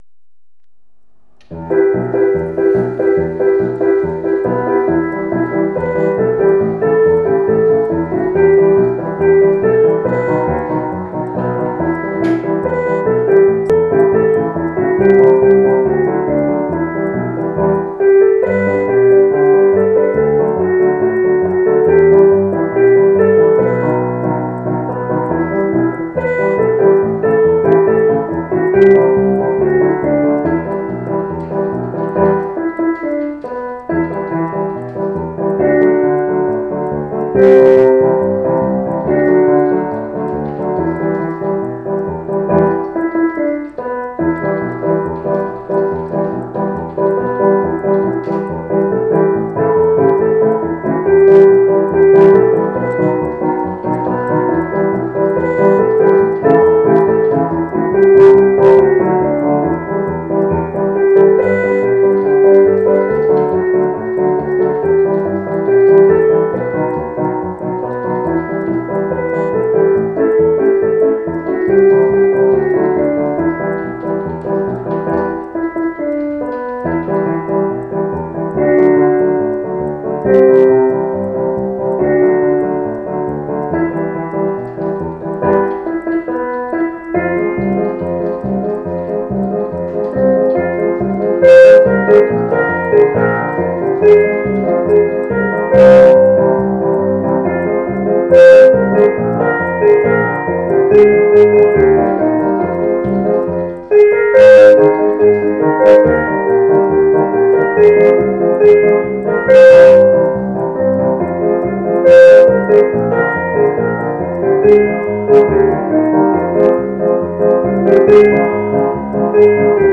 Reflections of a Lad at Sea (slow tempo, keyboard only)